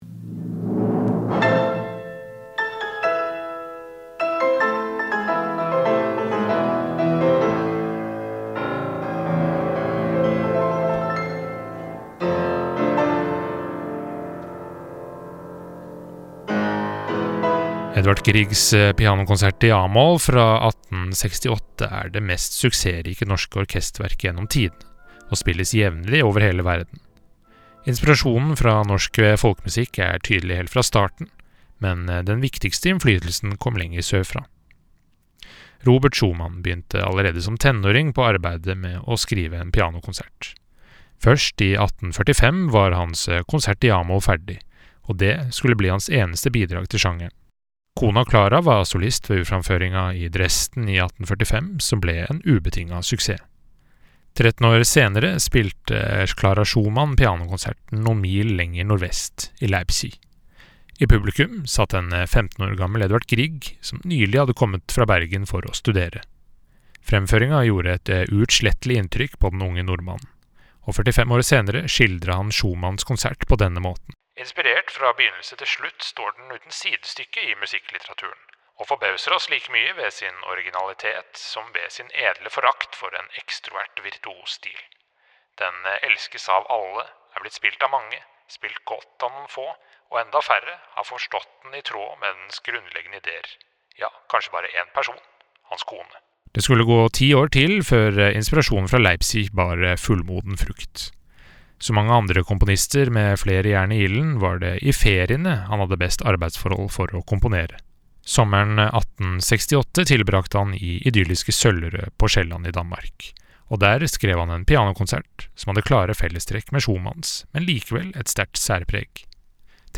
VERKOMTALE-Griegs-Pianokonsert.mp3